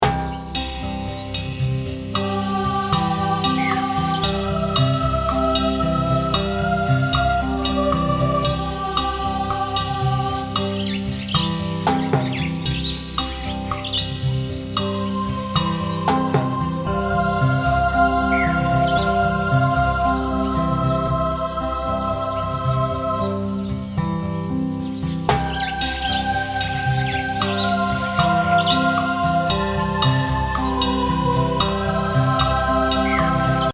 Haben Sie Lust auf den ewigen Frühling? Sehnen Sie sich nach moderaten Temperaturen, satten Farben und dem aufgeregten Gezwitscher der Vögel.